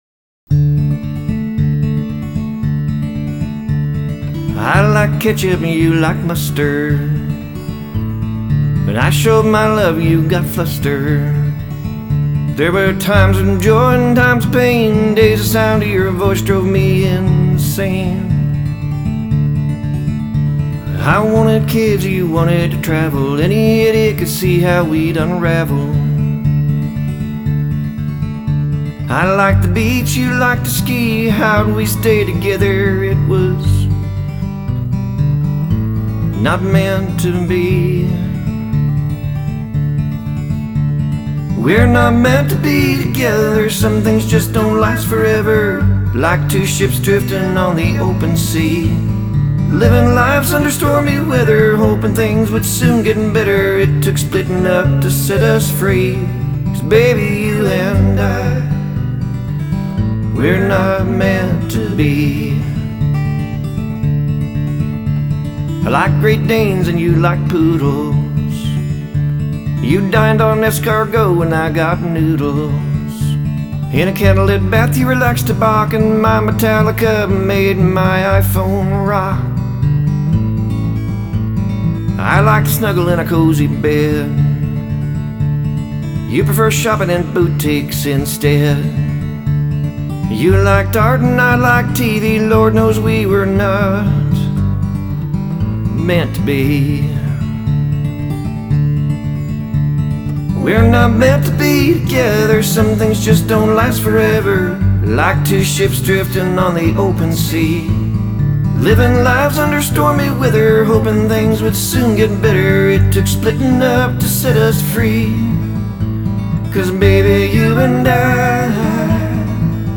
His voice is melodious and soothing.
The guitar play is hokey.
The instrumentals are just a guitar and a very light drum.
Artist was a standard country quality.
Picking was interesting, but didn't overpower the vocals.